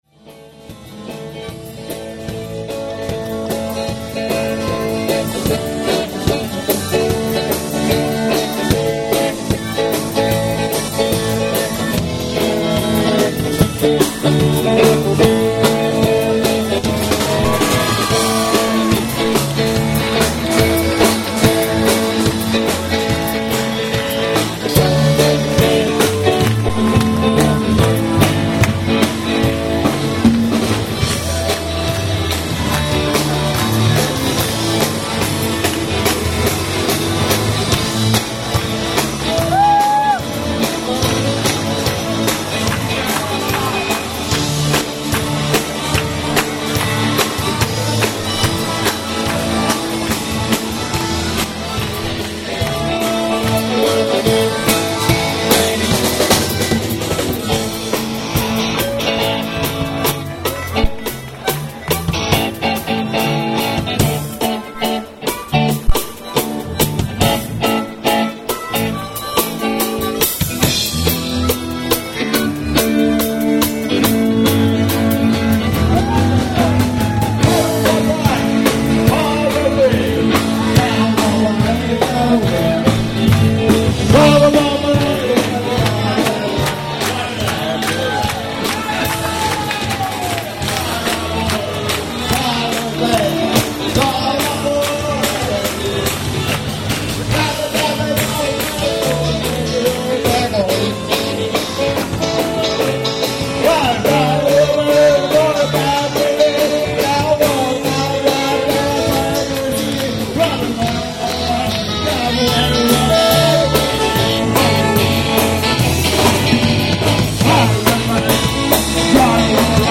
performed a nimble mouth organ solo